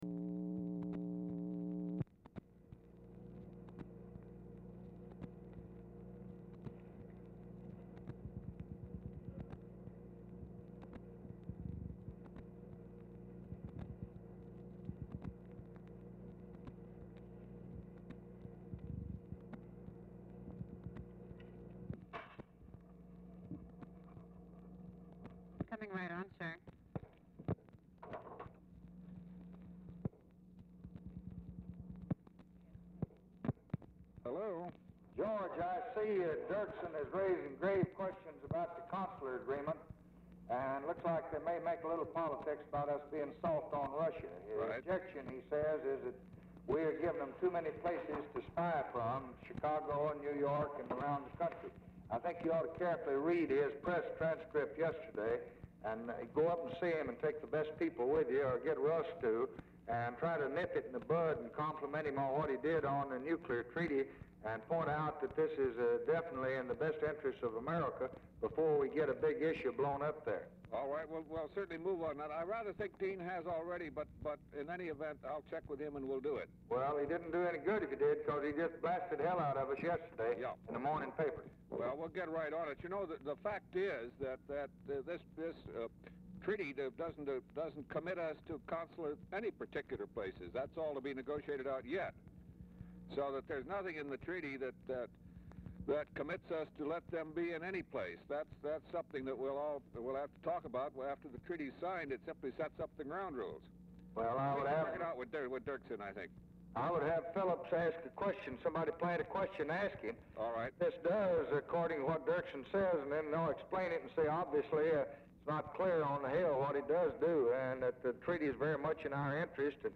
Oval Office or unknown location
OFFICE NOISE PRECEDES CALL
Telephone conversation
Dictation belt